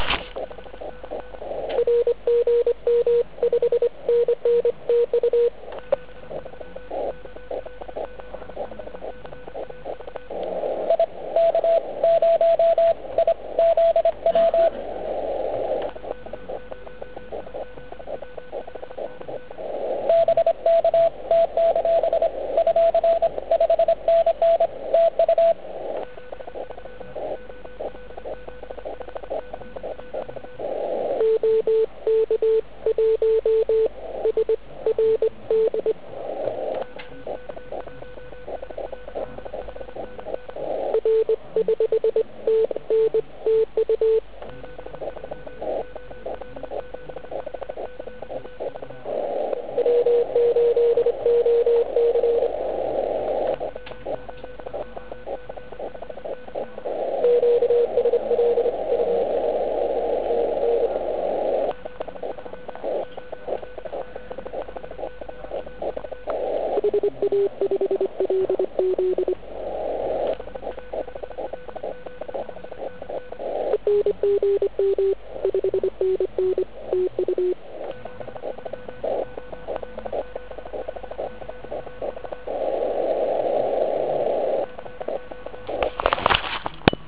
OL75CAV 80m CW (*.xls)
Spojení probíhala v rytmu - no asi jak je to v nahrávce.